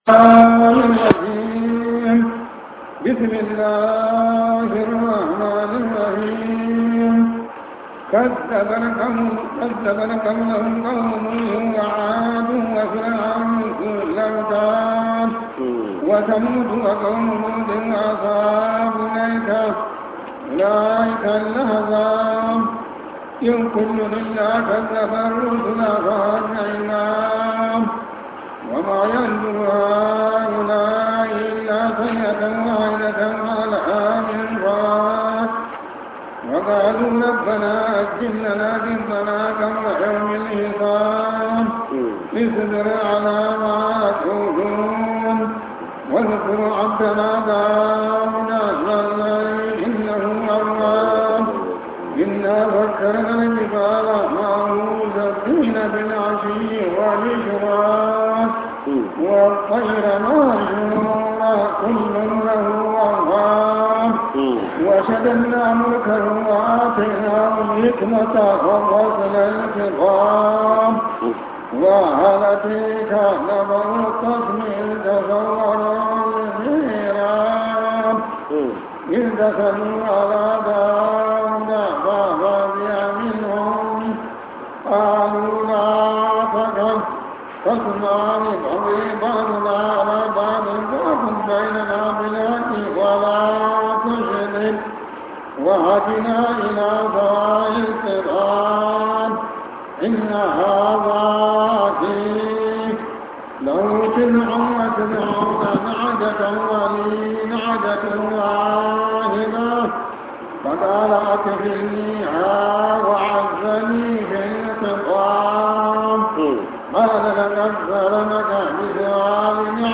Tafsir